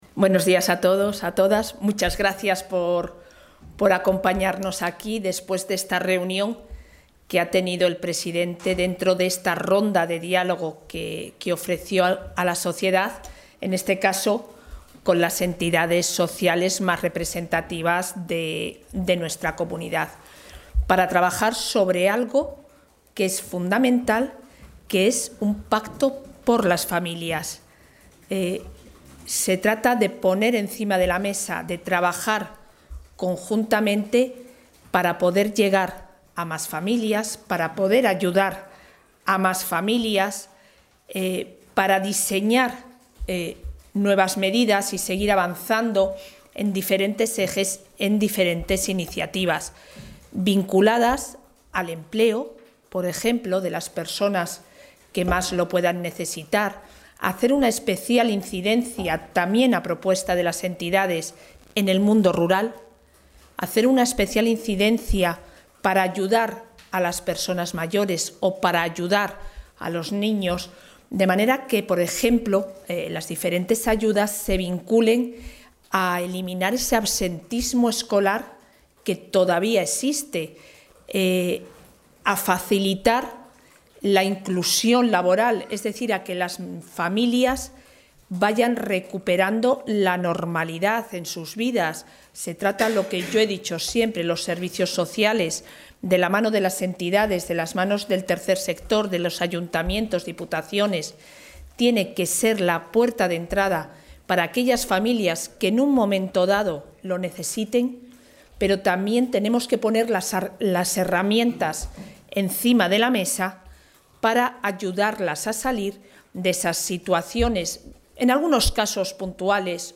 Valoración de la vicepresidenta de la Junta.